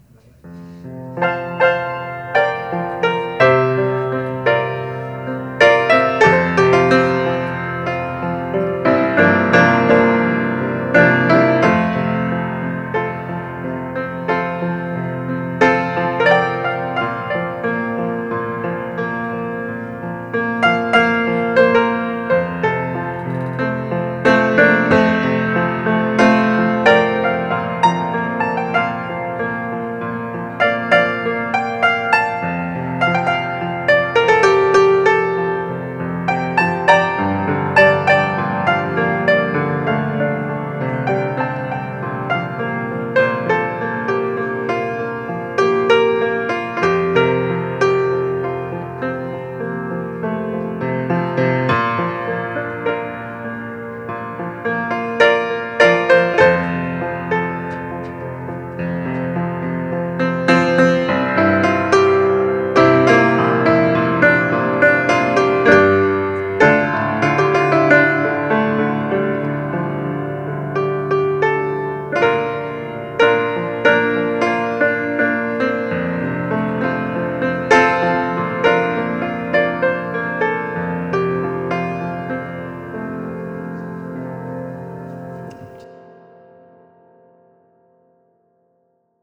Mercury Café Open Mic Nite